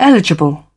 /ˈsʌb.dʒekt/